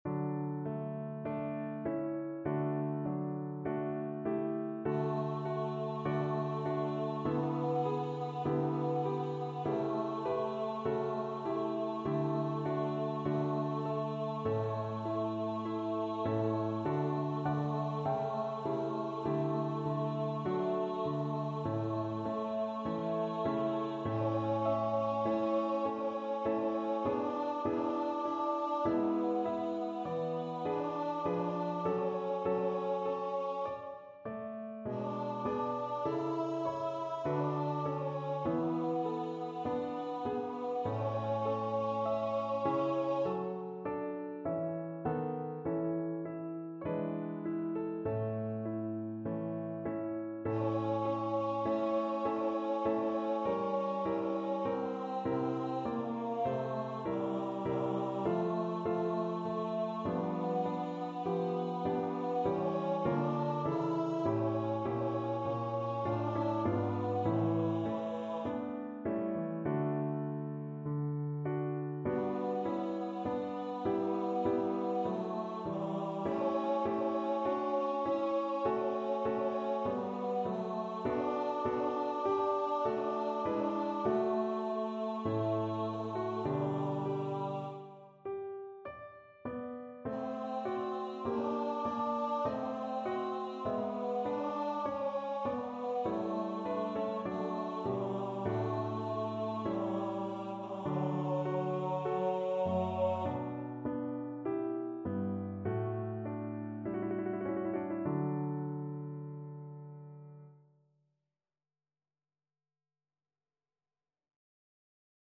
Free Sheet music for Choir (SATB)
"Ave verum corpus" is a short Eucharistic hymn that has been set to music by various composers.
D major (Sounding Pitch) (View more D major Music for Choir )
Adagio
2/2 (View more 2/2 Music)
Classical (View more Classical Choir Music)